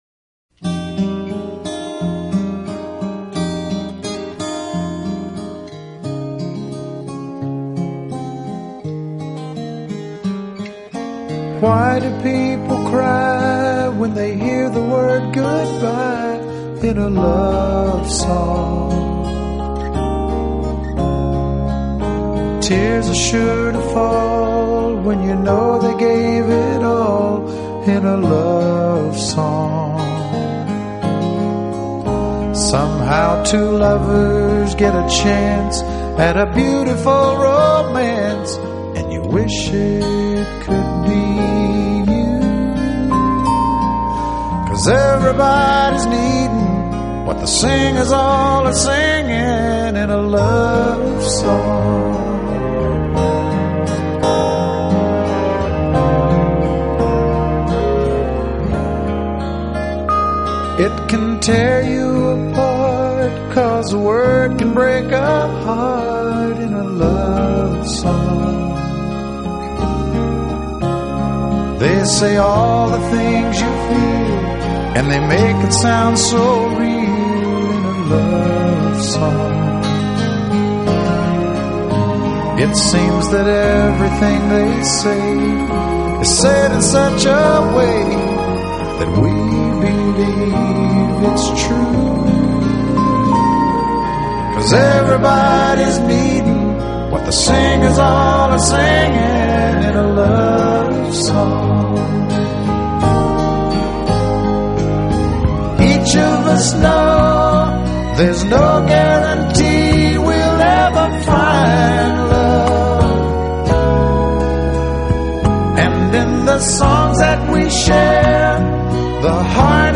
音乐风格 Genre: Country/乡村 | Songwriter | Duets